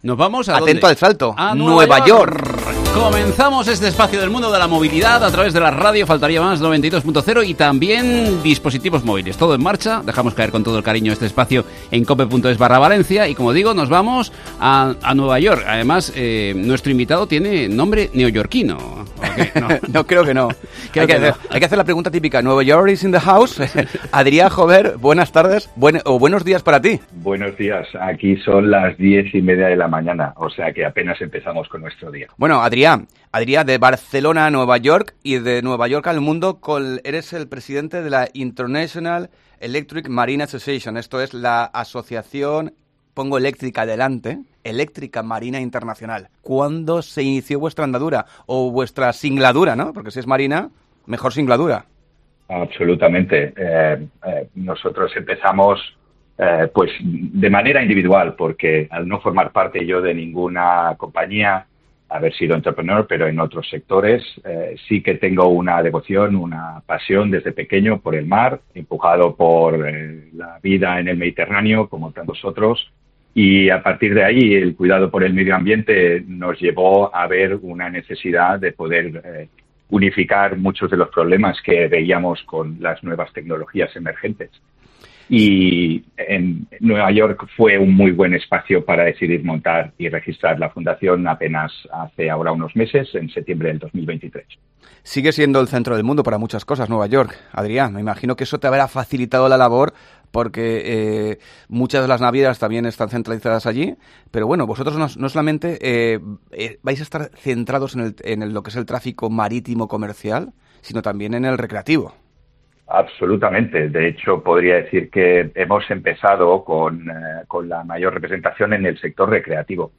AUDIO: Nos habla desde Nueva York, pero del 11 al 14 de abril Valencia será la capital de los barcos eléctricos, con el ELECTRIC BOAT SHOW